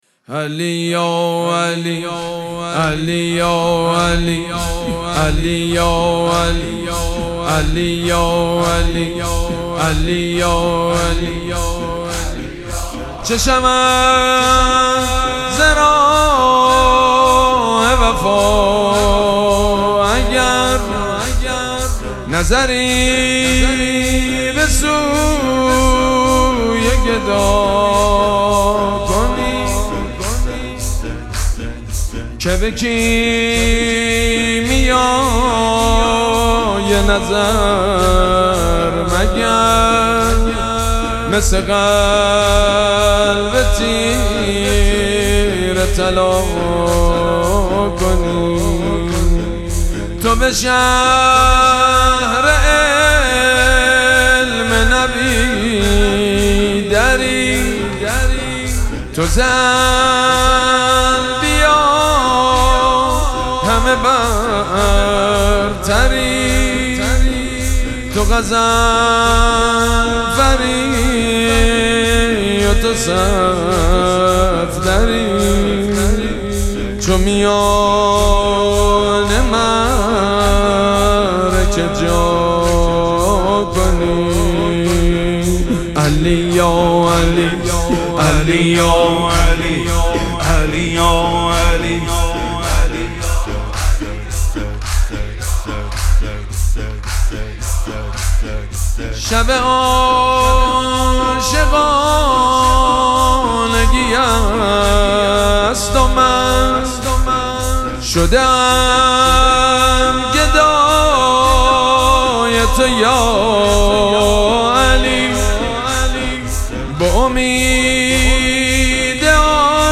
مراسم مناجات شب بیست و دوم ماه مبارک رمضان
مداح
حاج سید مجید بنی فاطمه